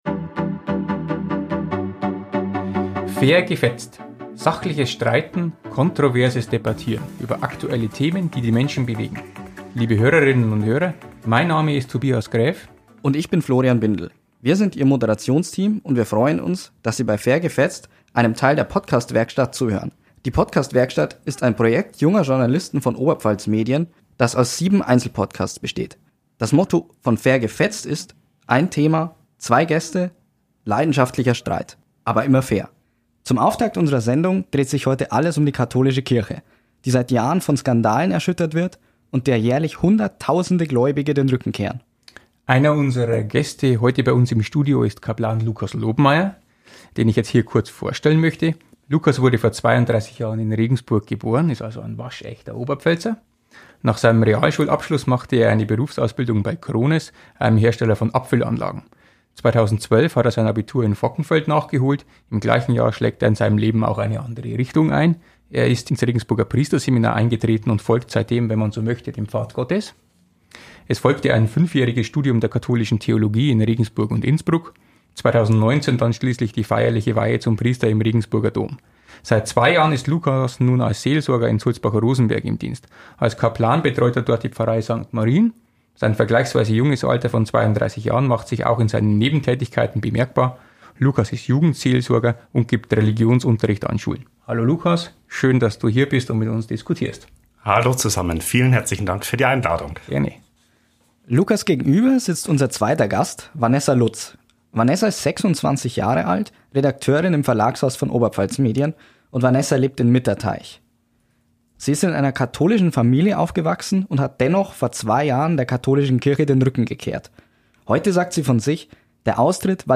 Streitgespräch: Kirchenkritikerin kontra Kaplan ~ Die Podcast-Werkstatt Podcast
Eine junge Frau, die von ihrer Kirche enttäuscht ist, und ein motivierter Kaplan: Im ersten Streitgespräch des Podcasts "Fair gefetzt" geht es um Kirchenaustritte, Missbrauch und die Frage, ob die katholische Kirche nun endgültig stirbt.